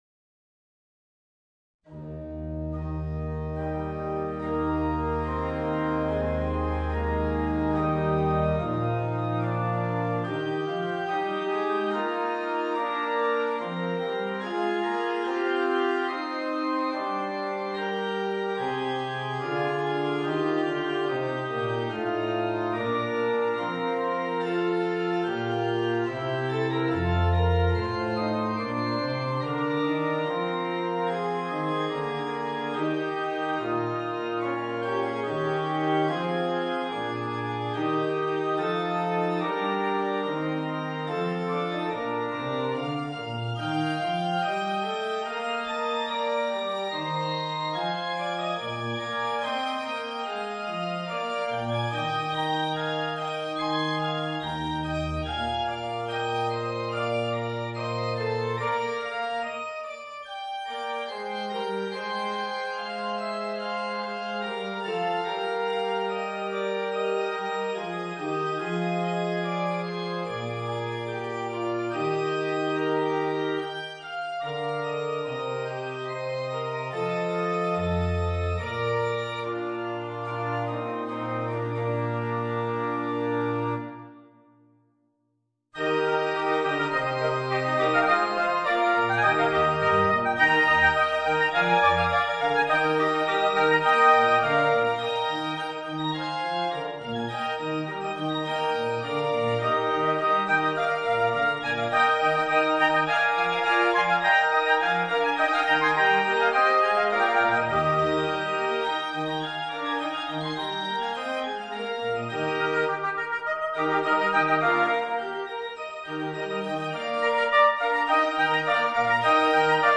Voicing: 2 Oboes and Organ